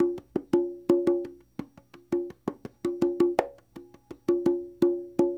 24 Conga 01.wav